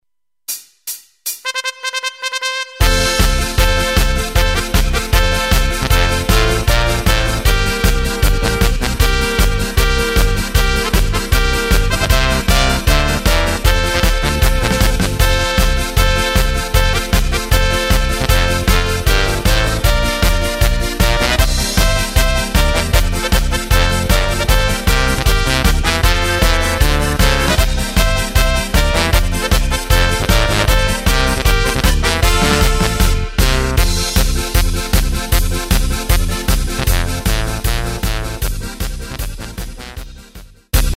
Takt:          2/4
Tempo:         155.00
Tonart:            F
Flotte Polka aus dem Jahr 2011!
Playback mp3 Demo